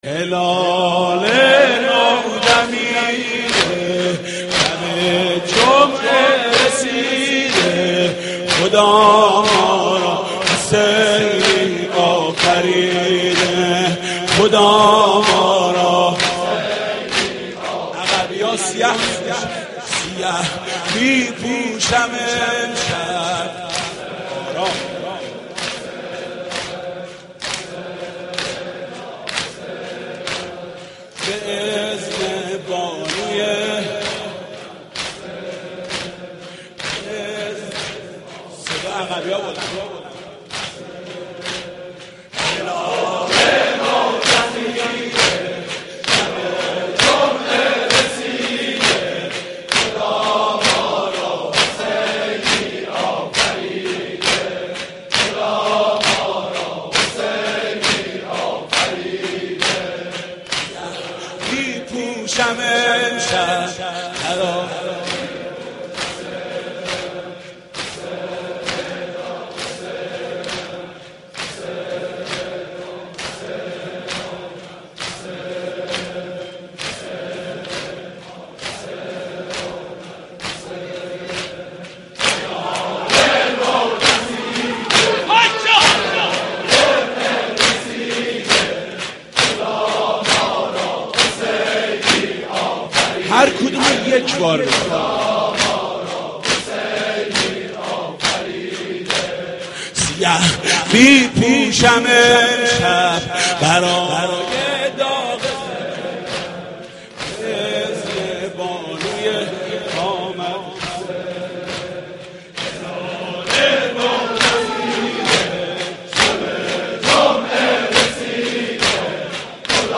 اشعار ورودیه محرم به همراه سبک با صدای حاج محمود کریمی/دودمه -( هلال نو دمیده شب جمعه رسیده )